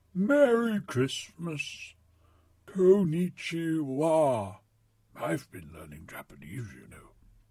And this is how he sounds. I went a big Gandalf in places...
PLAY SANTA VO SAMPLE